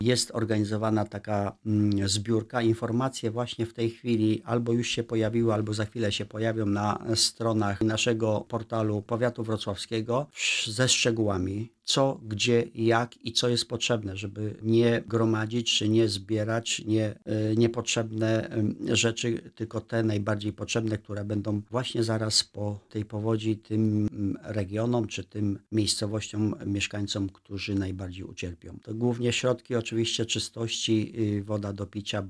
Hieronim Kuryś, czyli członek zarządu powiatu wrocławskiego był w poniedziałek gościem Radia Rodzina.